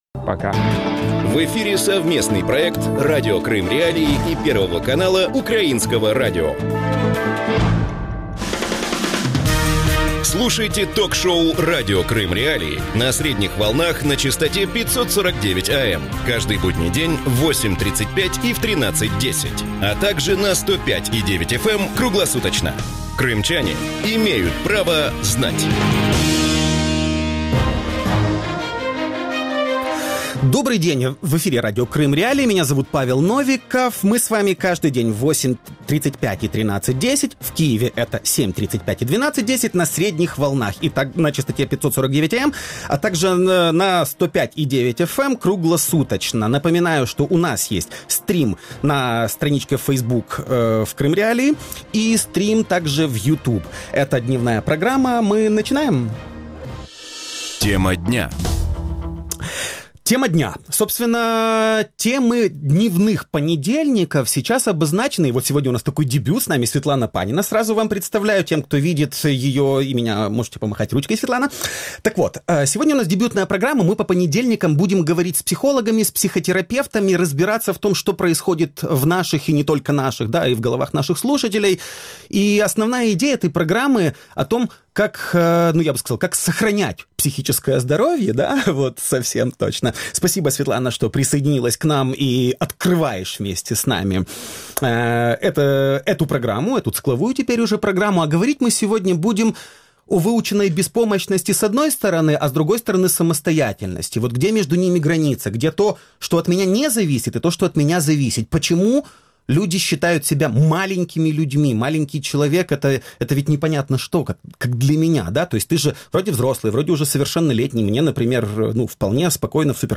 Мы люди маленькие? Интервью